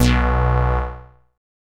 synth note02.wav